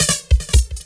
DRUMFILL06-L.wav